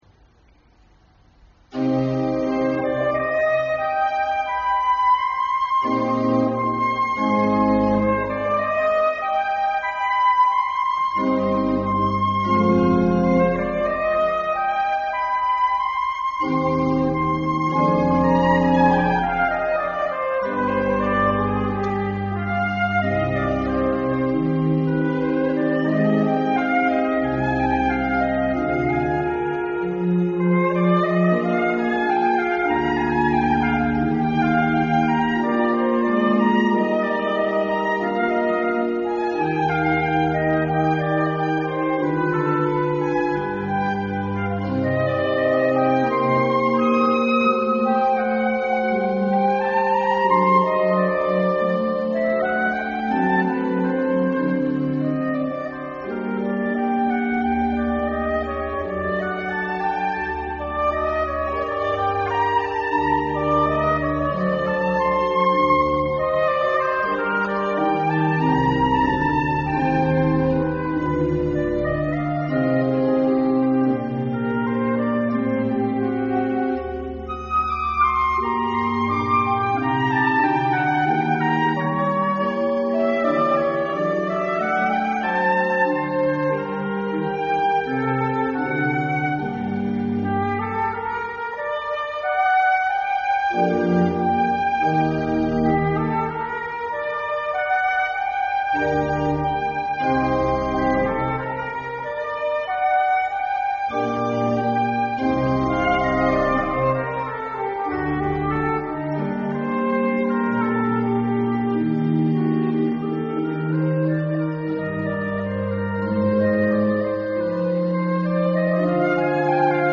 Flûte et orgue